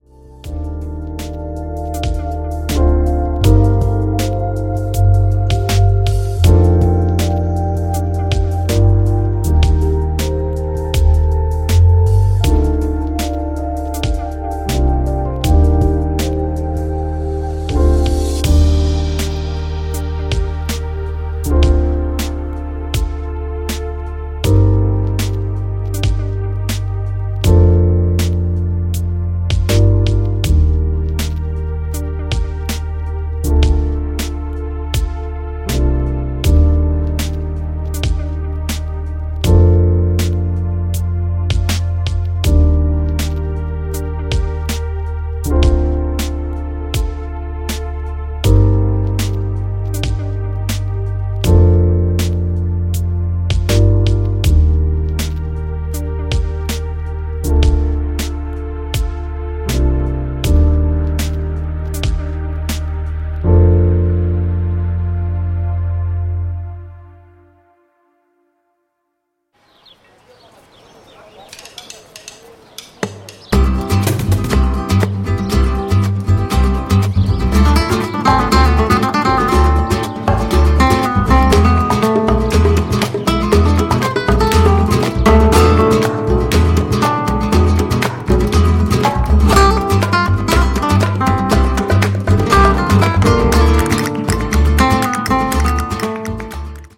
Temes musicals